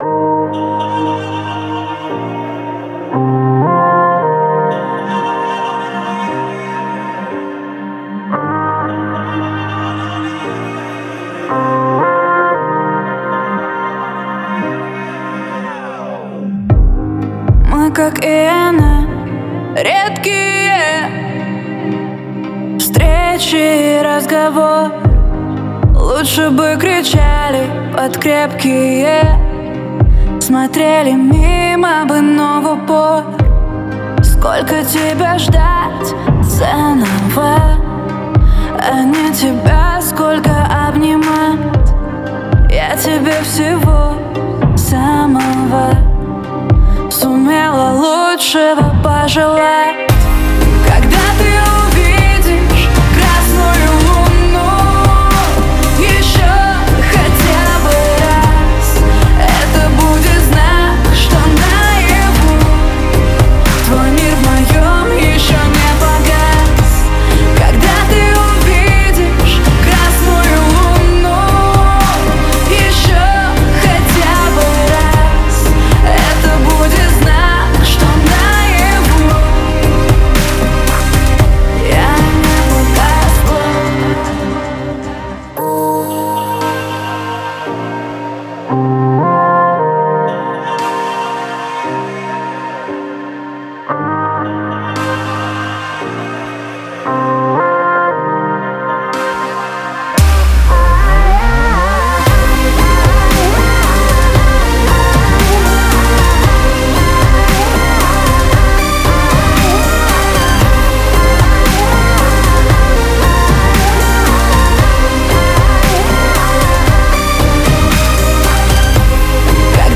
BPM115
Audio QualityMusic Cut